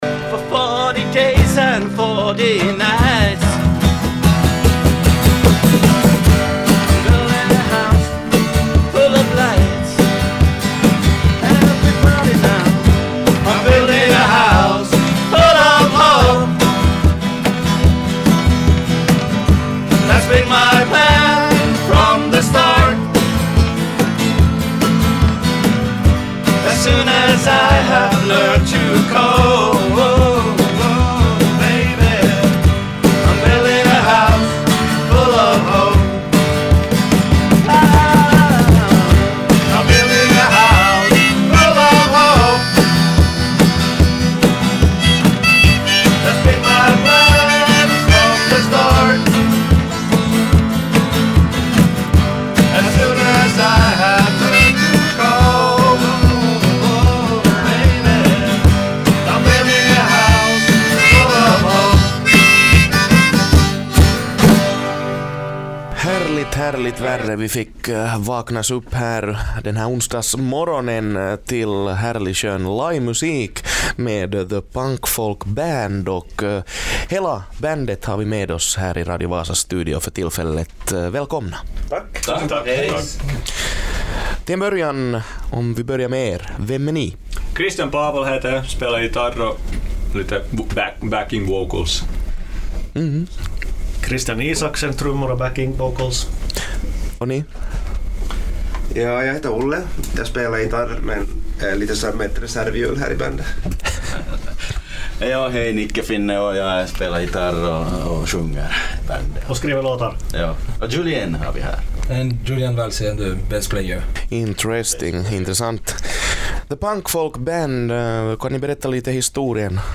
The Punk Folk Band besökte Radio Vasas studio före jul